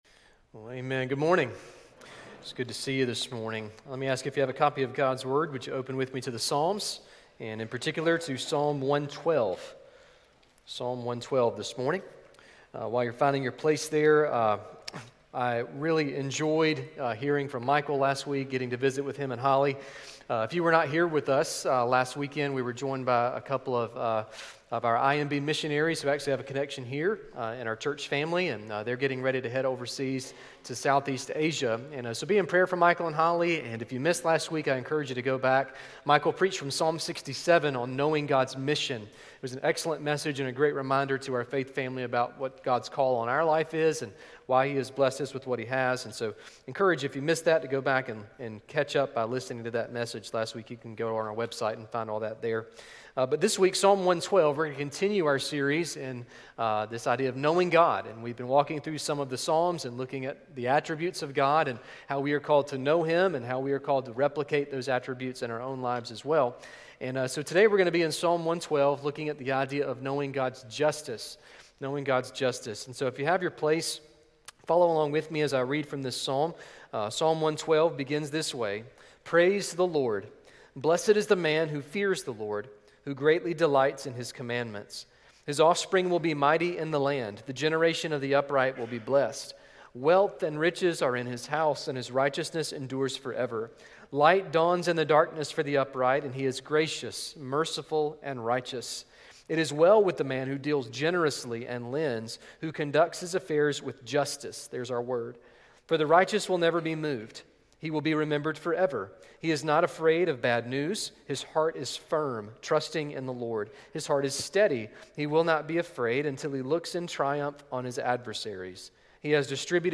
Sermon Listen Service Scripture References